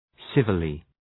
{‘sıvəlı}
civilly.mp3